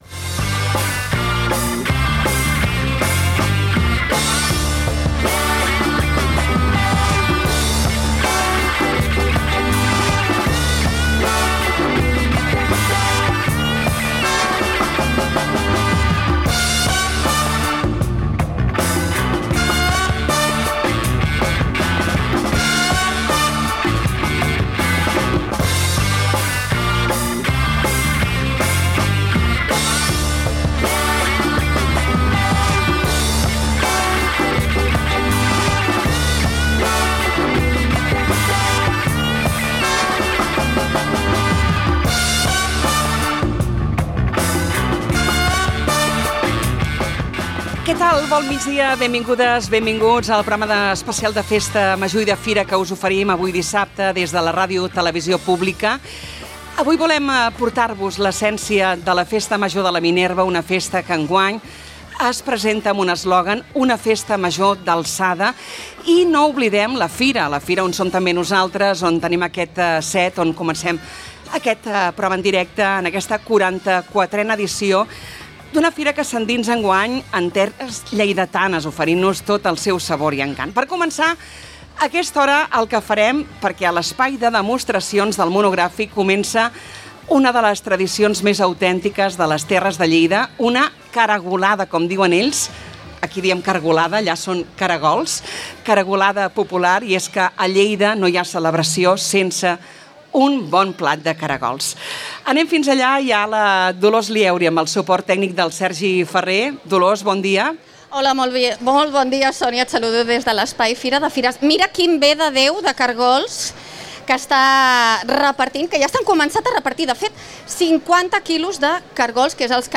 Programa especial des de la 44a Fira de Calella i l'Alt Maresme